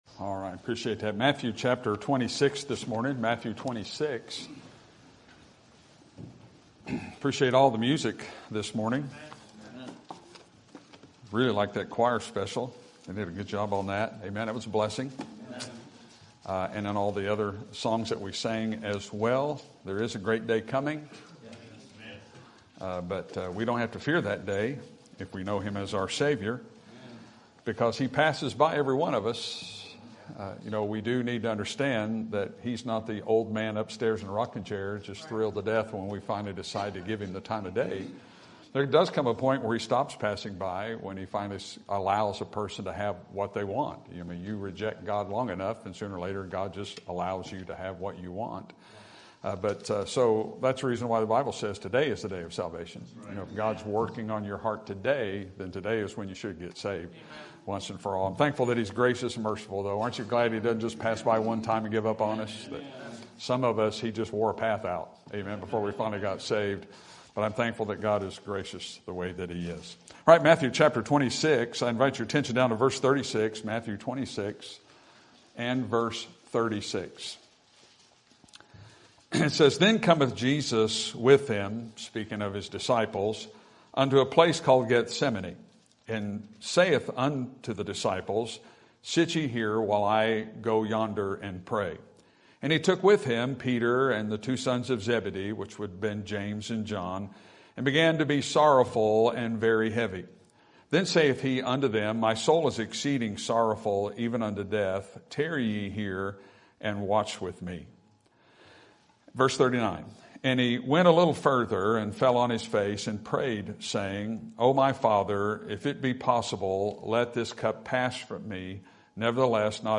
Sermon Topic: General Sermon Type: Service Sermon Audio: Sermon download: Download (23.65 MB) Sermon Tags: Matthew Jesus Opportunity Disciples